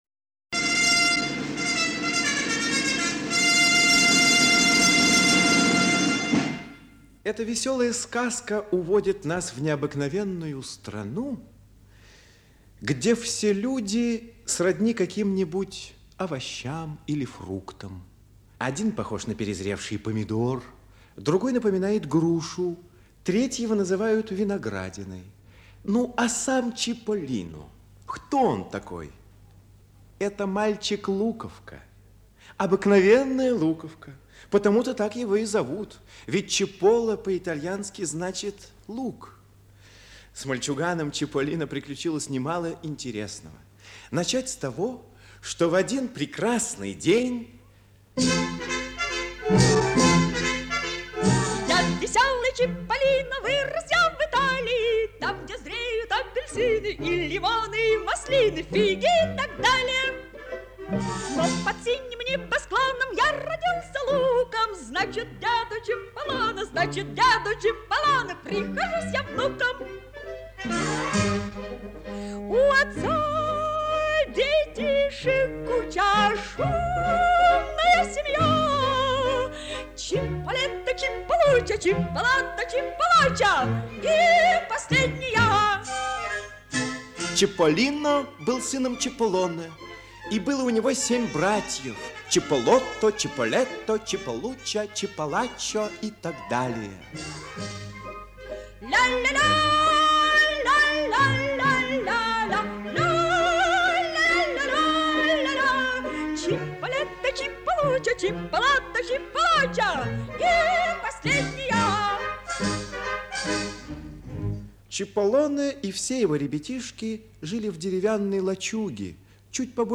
Приключения Чиполлино - аудиосказка Джанни Родари